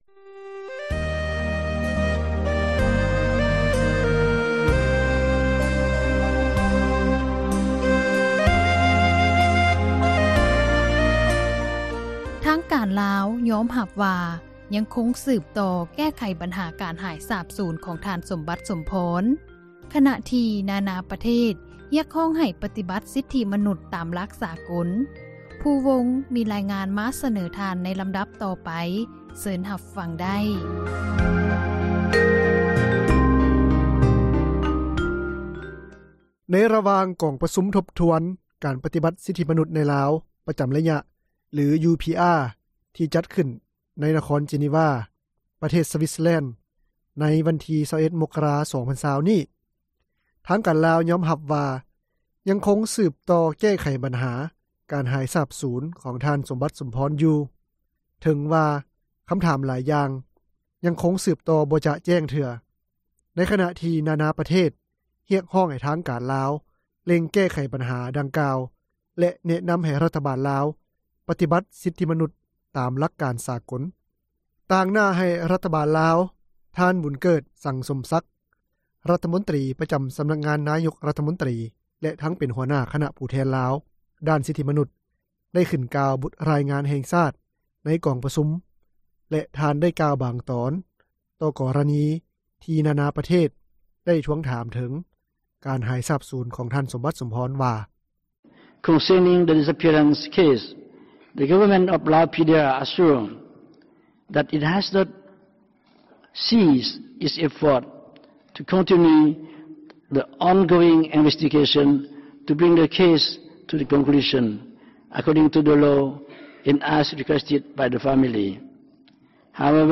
ຕາງໜ້າໃຫ້ຣັຖບານລາວ, ທ່ານ ບຸນເກີດ ສັງສົມສັກ, ຣັຖມົນຕຣີປະຈຳສຳນັກງານ ນາຍົກຣັຖມົນຕຣີ ແລະ ທັງເປັນຫົວໜ້າຄນະຜູ້ແທນ ລາວ ດ້ານສິທທິມະນຸດ ໄດ້ຂຶ້ນກ່າວ ບົດຣາຍງານແຫ່ງຊາຕ ໃນກອງປະຊຸມ ແລະ ທ່ານໄດ້ກ່າວບາງຕອນ ຕໍ່ກໍຣະນີ ທີ່ນາໆປະເທດ ໄດ້ທວງຖາມເຖິງ ການຫາຍສາບສູນ ຂອງທ່ານ ສົມບັດ ສົມພອນ ວ່າ: